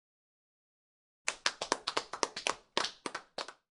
golfClaps.mp3